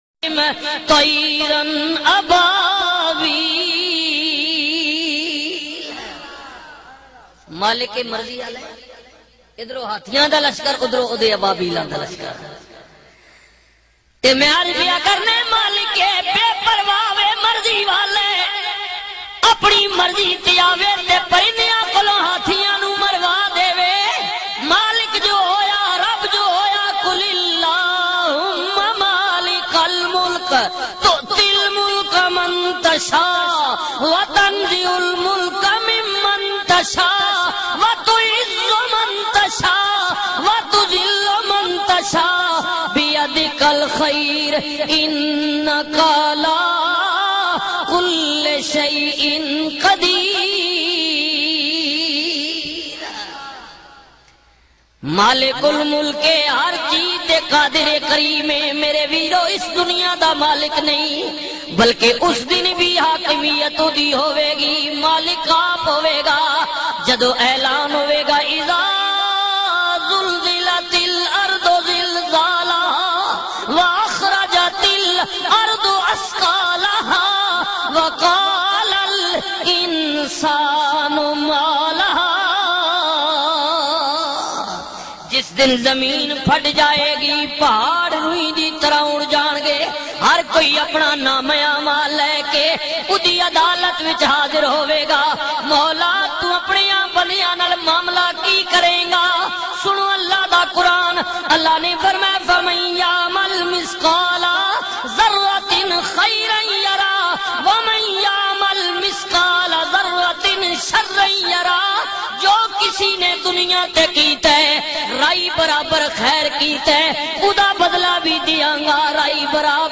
Beautiful bayan